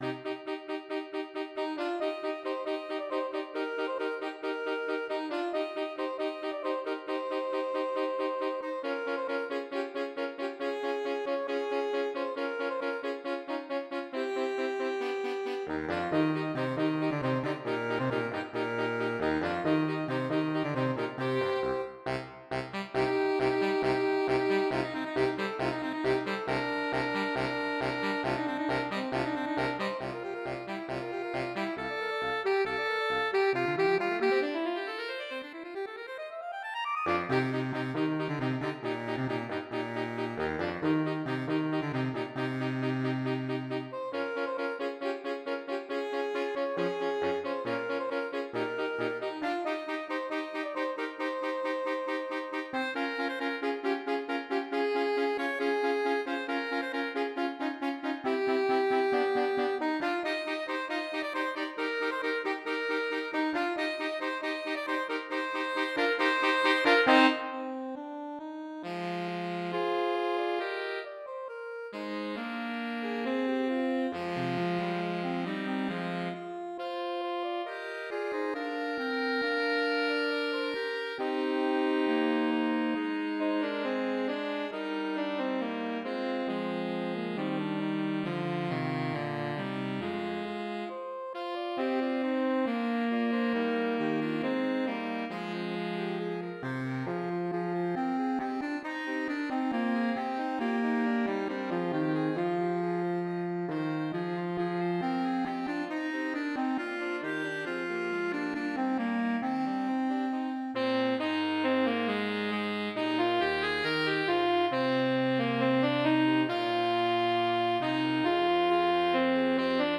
mp3 SCORE First Suite in Eb, Mvmt 2. Intermezzo [Gustav Holst] 5 SATB.
Fast and exciting.